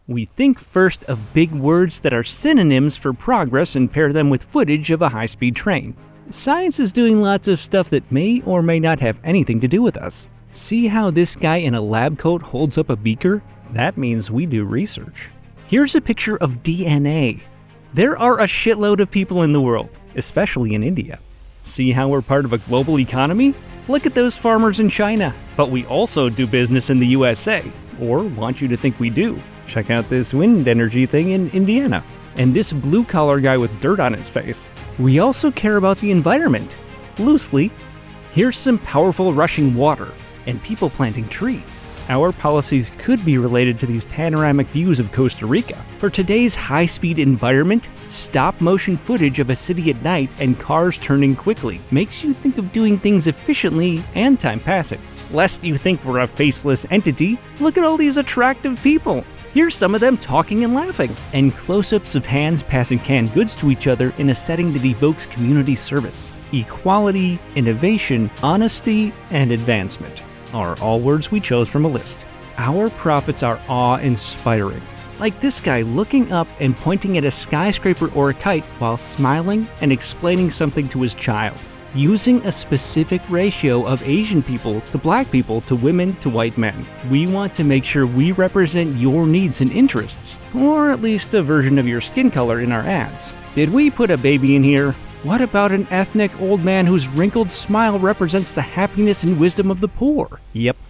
Male
Yng Adult (18-29), Adult (30-50)
Character / Cartoon
Characters For Animation
Corporate
b2b Corporate Demo